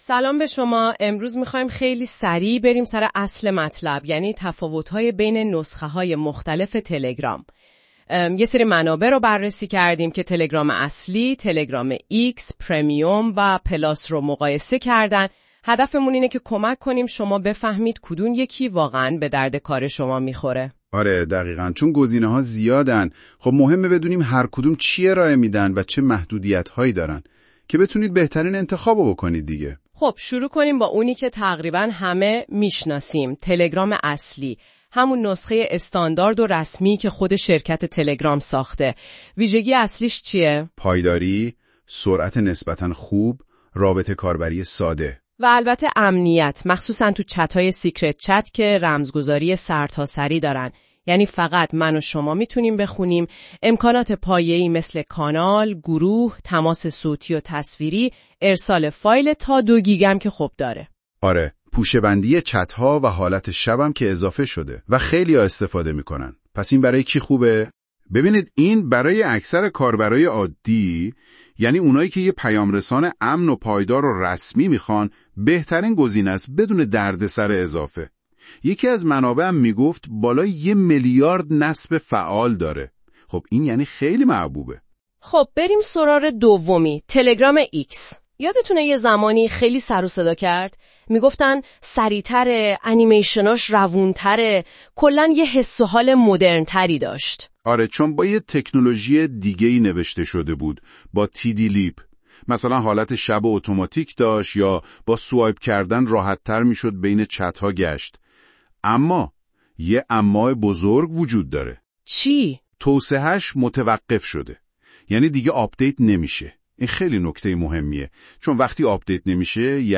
راهنمای صوتی استفاده از نسخه های مختلف تلگرام: